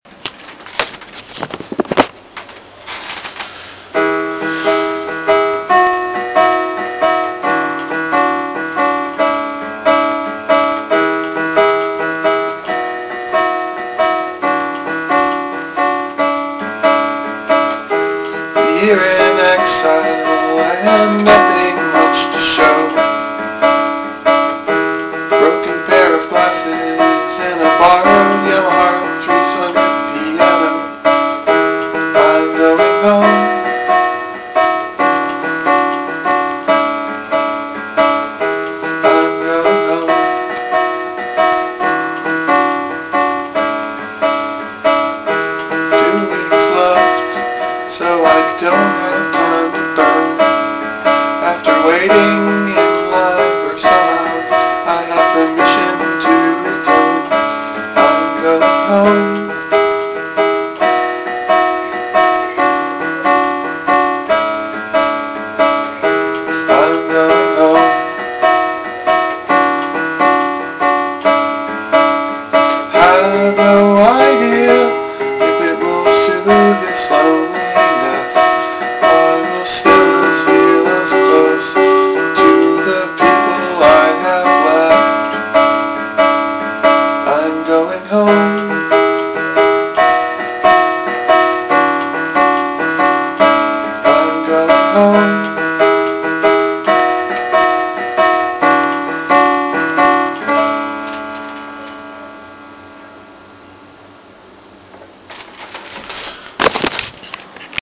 An unstudied 10 minute sketch can certainly showcase one's cliches.
Filed under: Song | Comments (4)